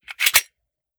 fps_project_1/45 ACP 1911 Pistol - Magazine Load 003.wav at fc29636ee627f31deb239db9fb1118c9b5ec4b9f